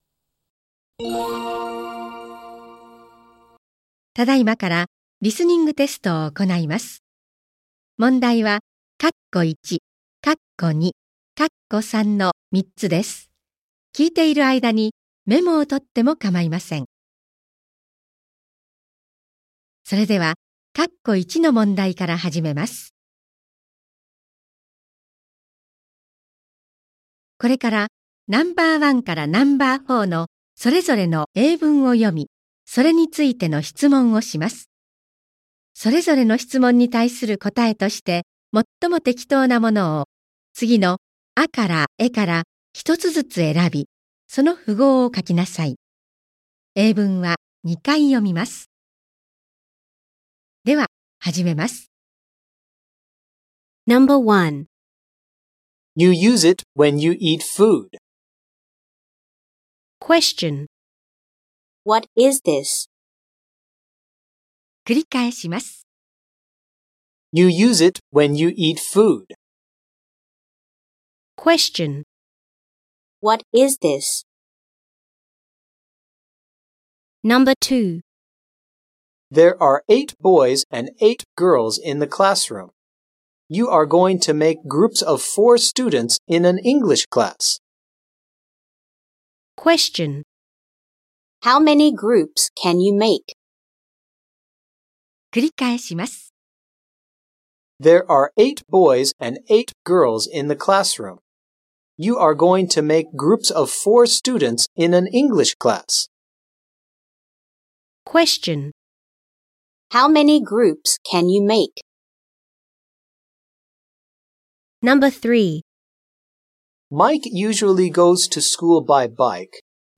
英語リスニング